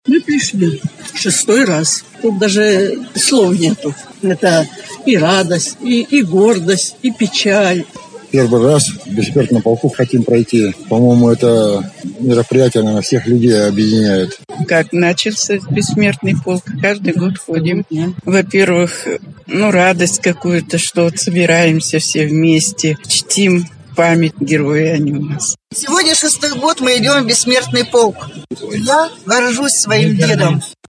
Корреспондент Службы Новостей "FM-Продакшн" пообщался с участниками шествия и узнал, зачем они принимают участие в этой акции, что ими движет и для чего многие шагают в полку уже несколько лет подряд.